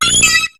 Cri d'Hypotrempe dans Pokémon HOME.